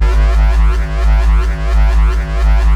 Desecrated bass hit 17.wav